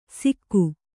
♪ sikku